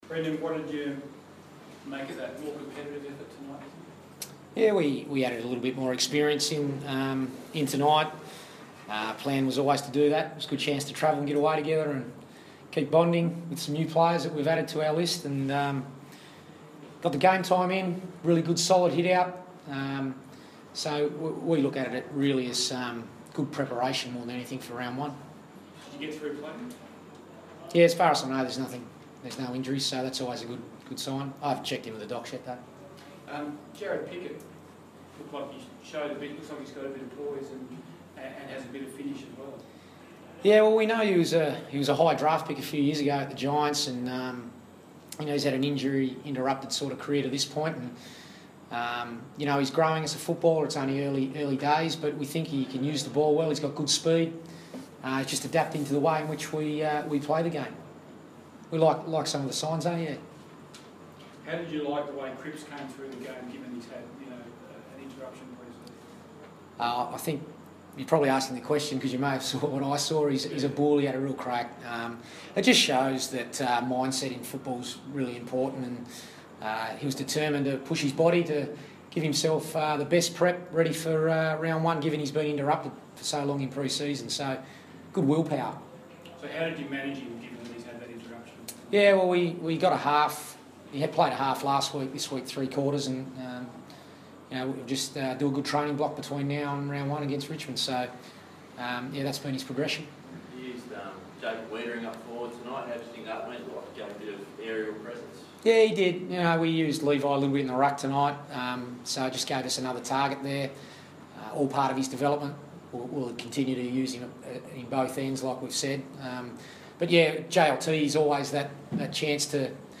Brendon Bolton post-match press conference - JLT 3